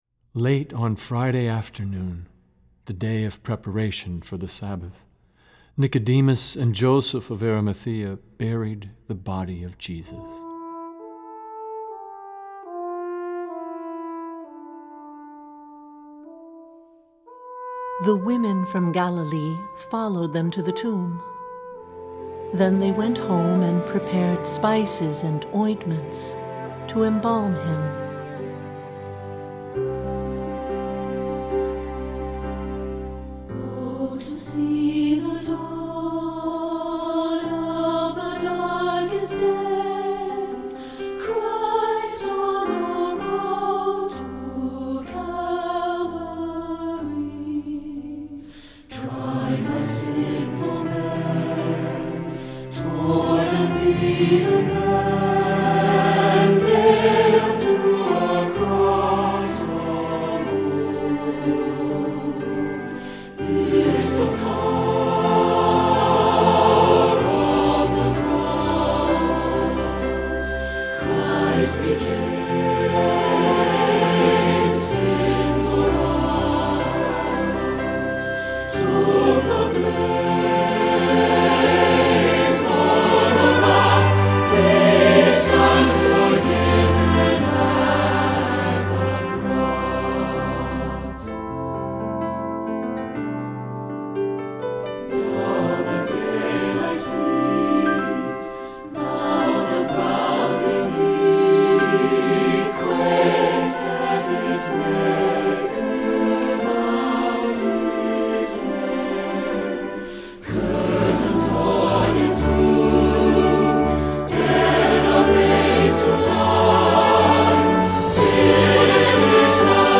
Voicing: S/a/t/b - Rehearsal